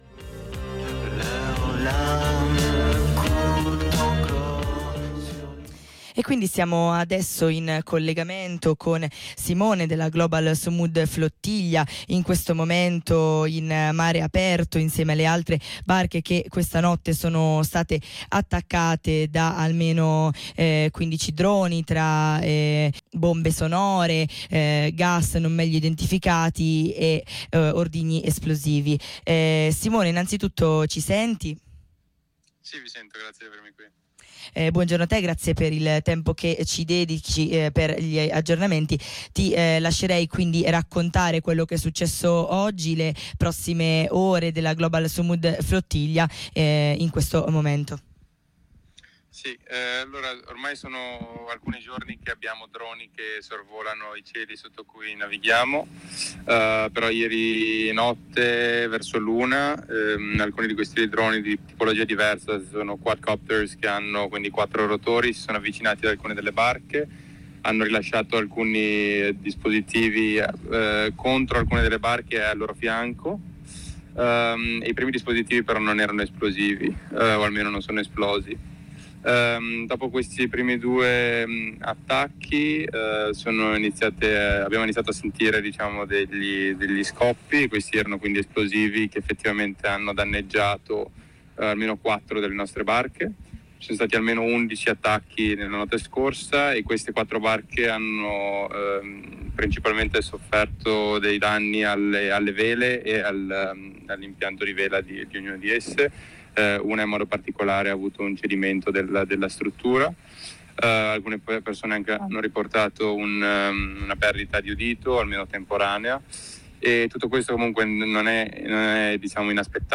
ai nostri microfoni: